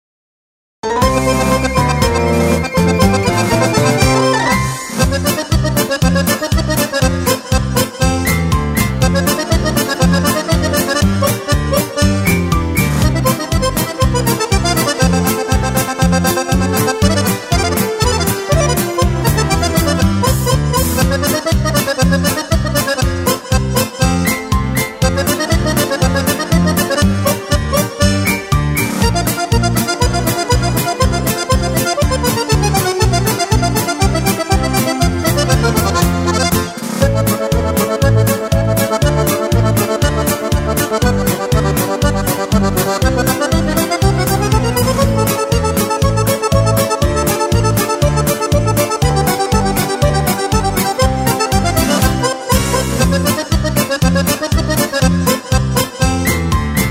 Playback + Akkordeon noten